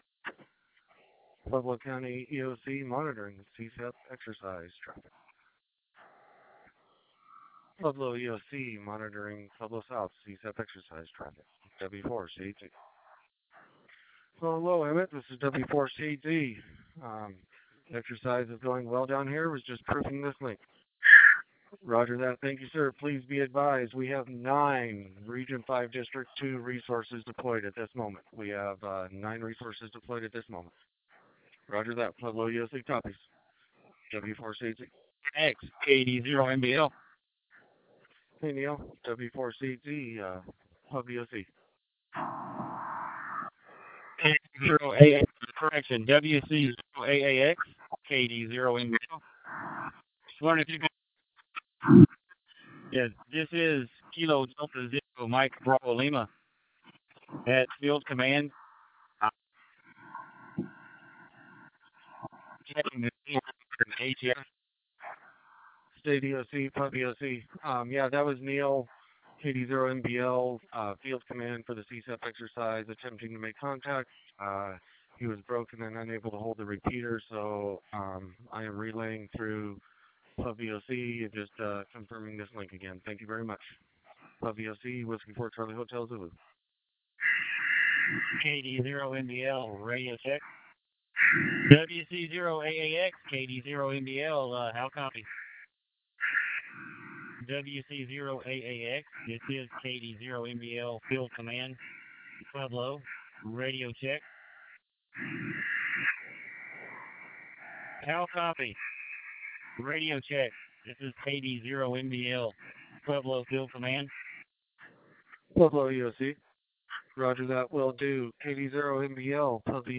Audio from the RMHAM DMR system on the Colorado South Talkgroup. You can only hear Pueblo traffic because the bridge wouldn’t allow me to capture the traffic coming from the Devils Head site.